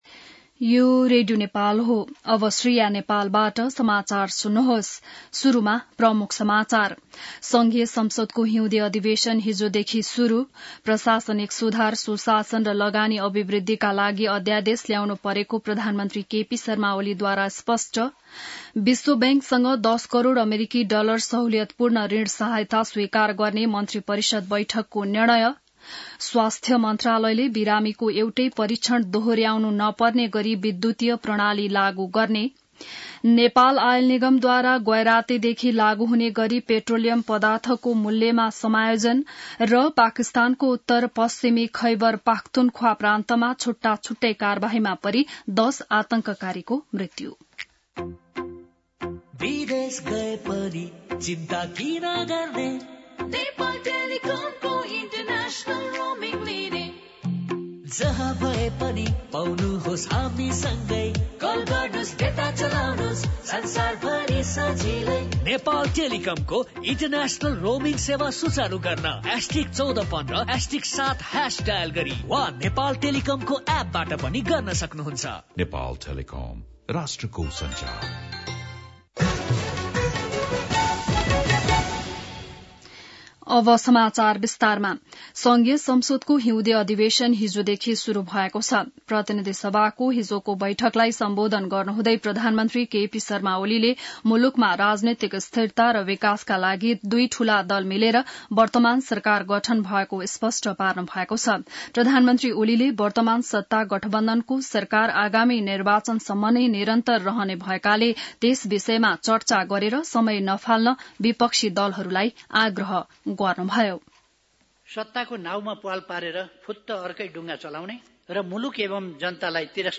An online outlet of Nepal's national radio broadcaster
बिहान ७ बजेको नेपाली समाचार : २० माघ , २०८१